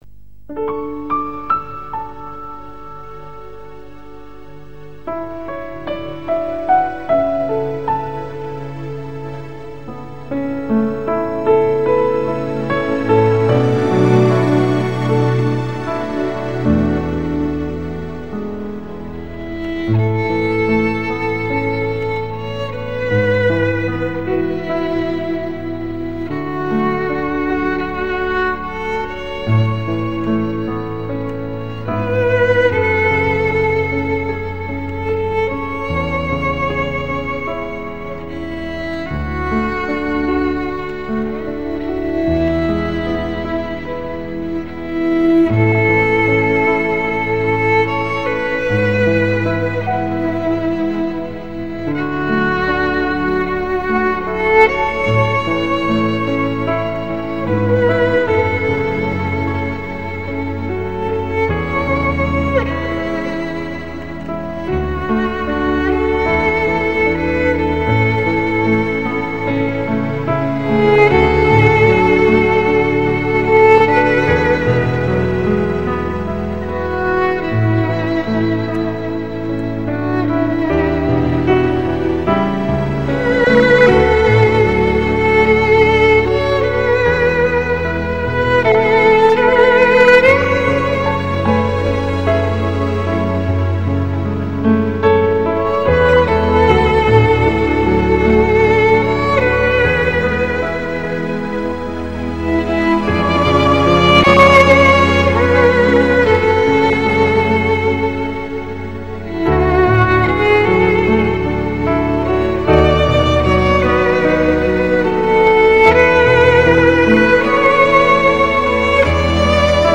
[19/12/2009][音乐鉴别]一首小提琴乐曲，求助曲名！